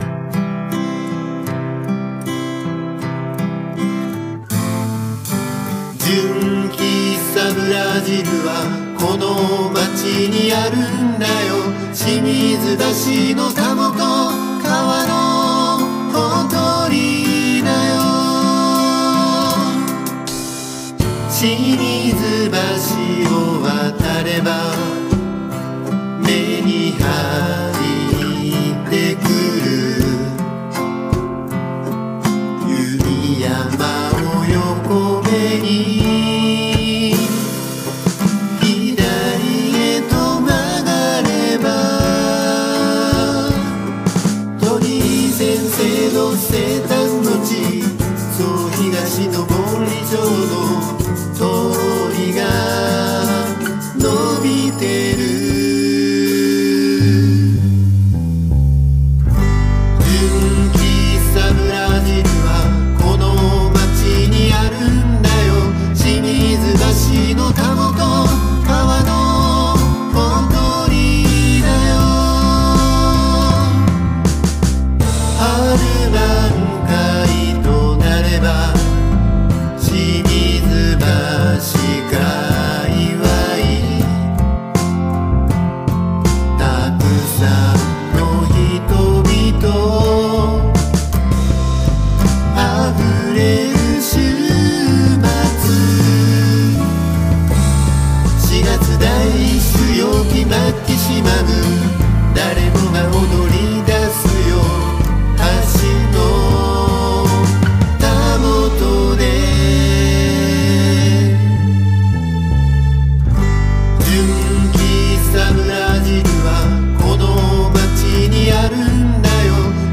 ドラム：Steven Slate Drums 5.5
アコギ：Epiphone EJ-160E
良い雰囲気です。